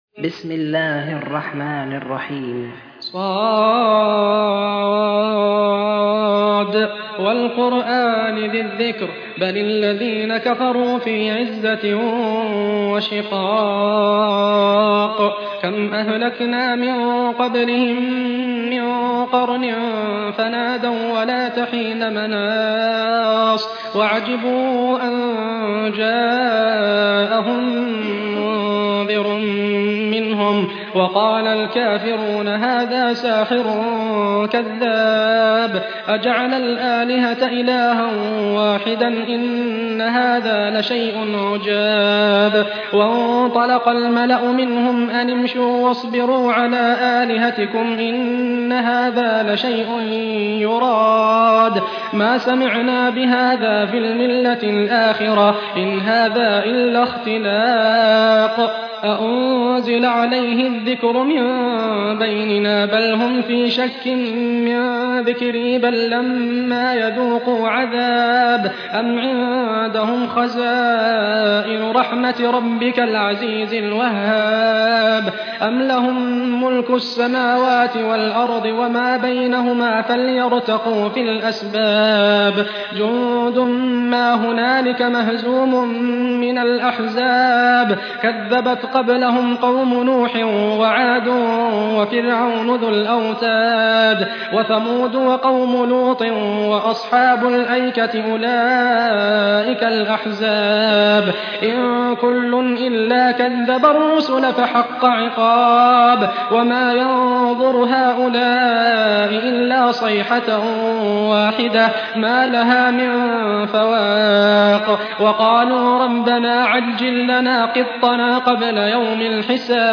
High Quality Quranic recitations and Islamic Lectures from selected scholars